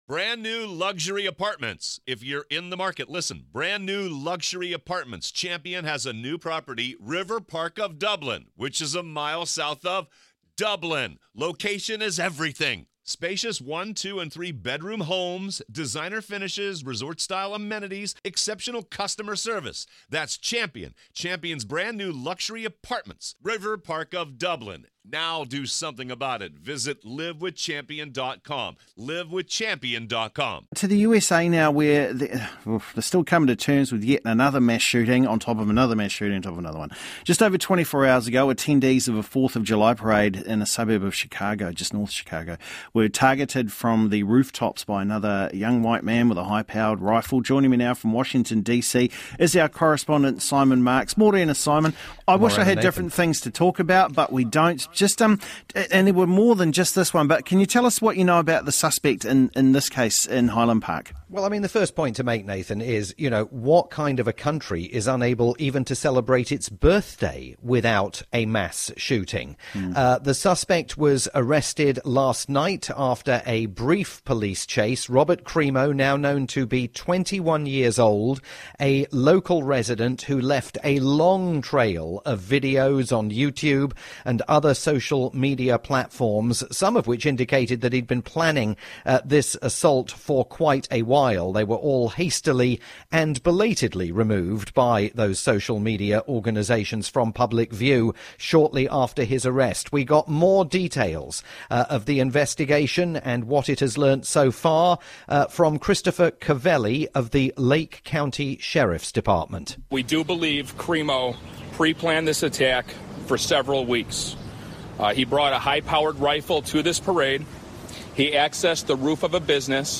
live update for Radio New Zealand's "First Up"